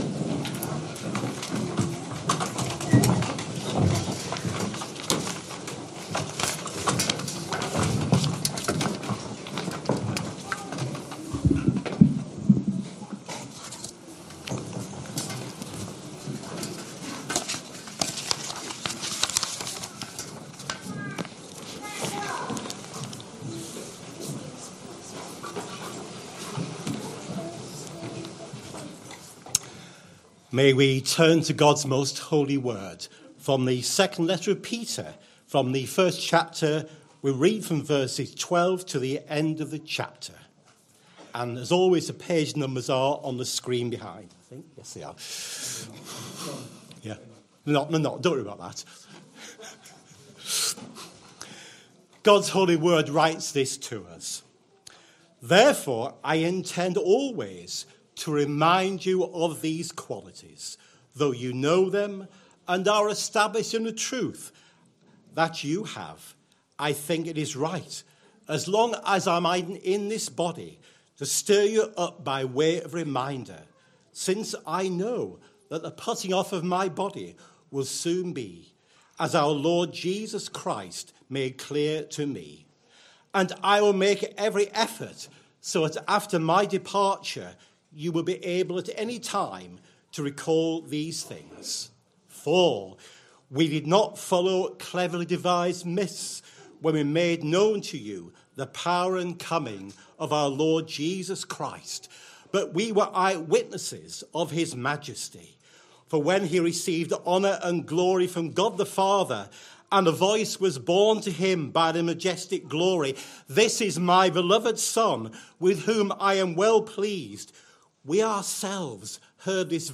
Sunday AM Service Sunday 4th January 2026 Speaker